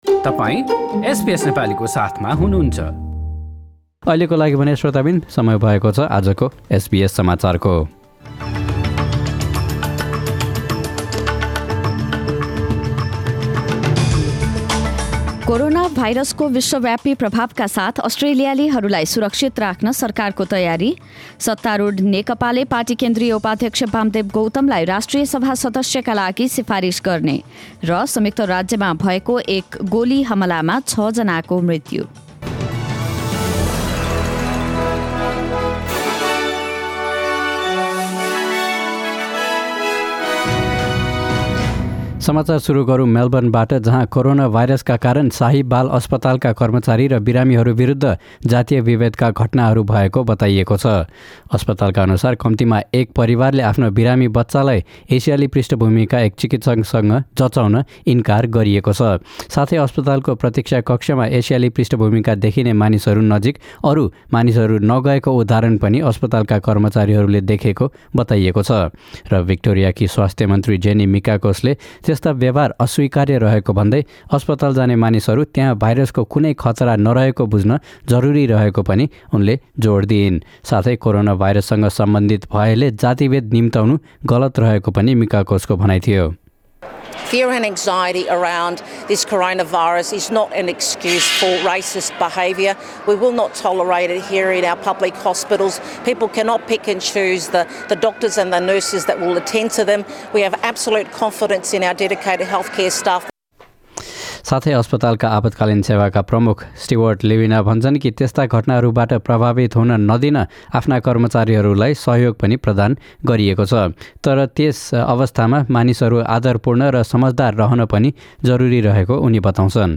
एसबीएस नेपाली अस्ट्रेलिया समाचार: बिहीवार २७ फेब्रुअरी २०२०